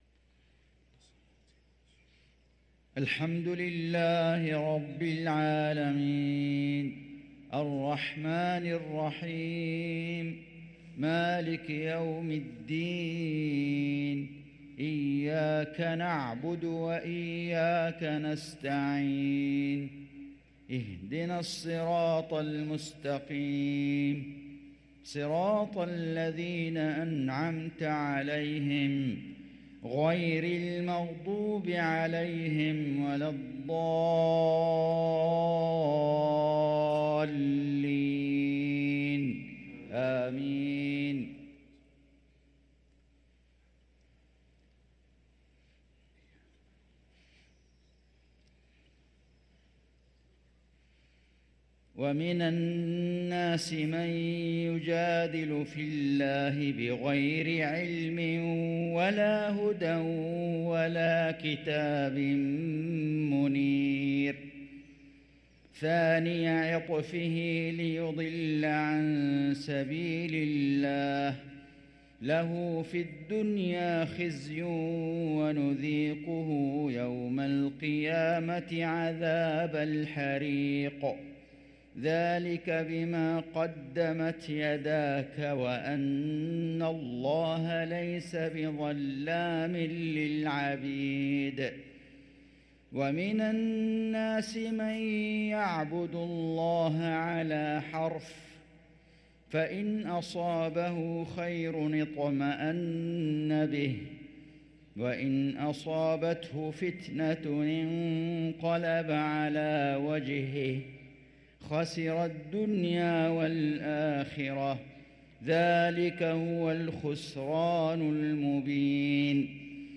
صلاة المغرب للقارئ فيصل غزاوي 18 جمادي الآخر 1444 هـ
تِلَاوَات الْحَرَمَيْن .